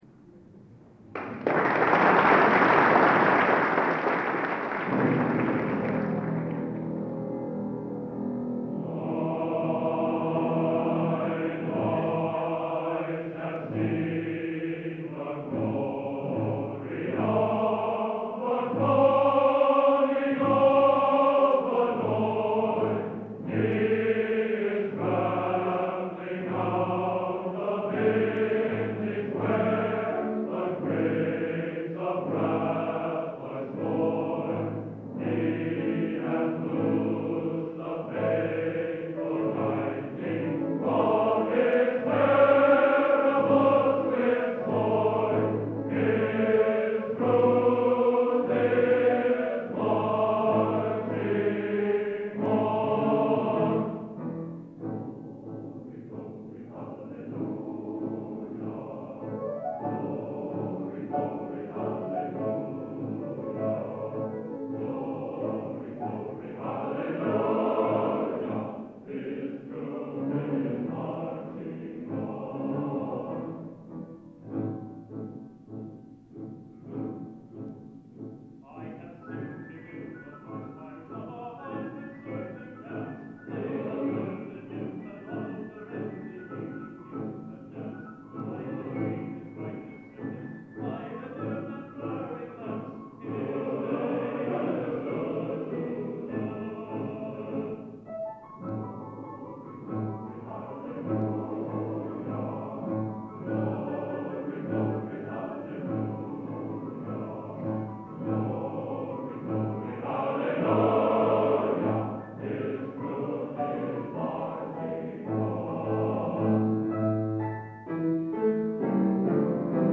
Location: Florence, Italy